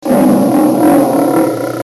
lion.mp3